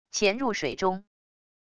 潜入水中wav音频